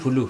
[pulu] noun snake